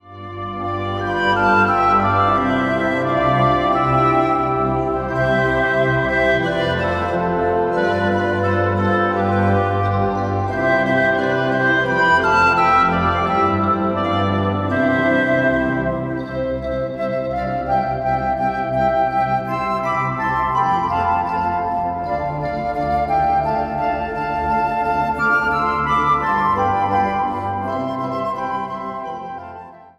Instrumentaal | Dwarsfluit
Instrumentaal | Hobo
Instrumentaal | Klarinet
Instrumentaal | Marimba
Instrumentaal | Panfluit
Instrumentaal | Saxofoon
Instrumentaal | Synthesizer
Instrumentaal | Trompet
Instrumentaal | Xylofoon
Instrumentaal | Viool